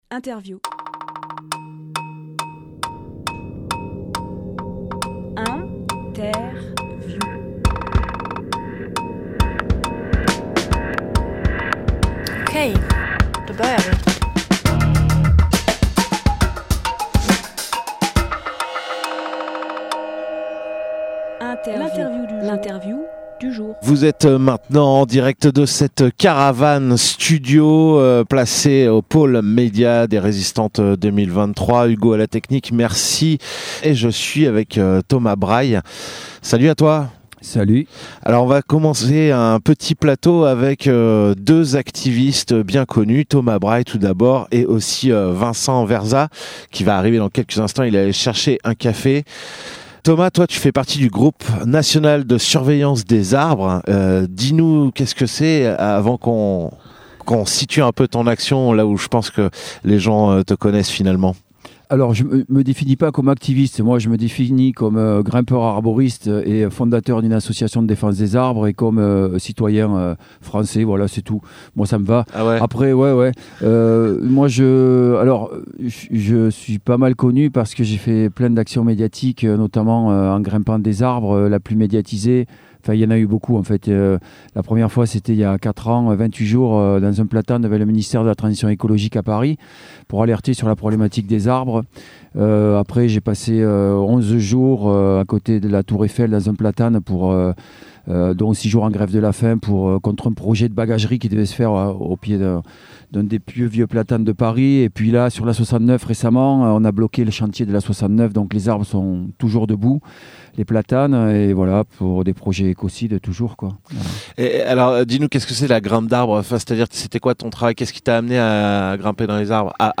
Emission - Interview
Télécharger en MP3 Les Résistantes 2023, rencontres des luttes locales et globales du 3 au 6 août 2023 sur le plateau du Larzac.
Lieu : Plateau du Larzac